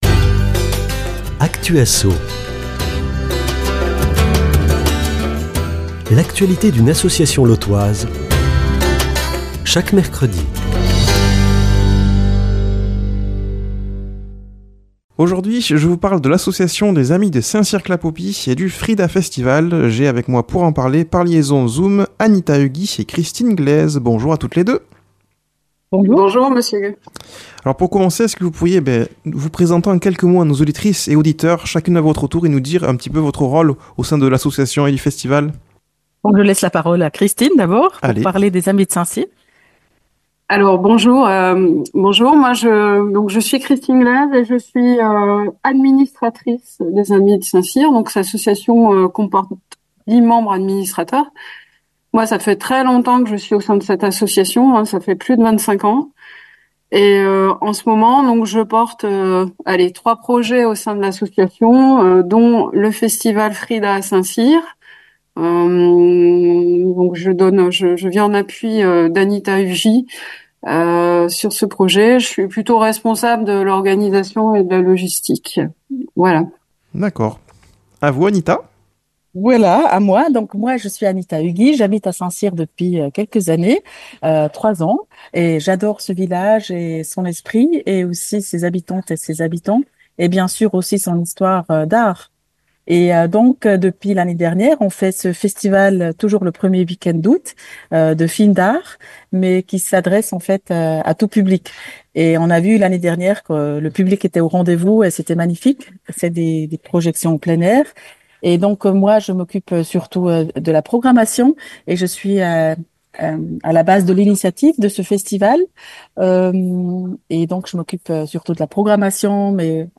par liaison Zoom